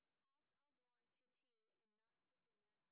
sp14_street_snr10.wav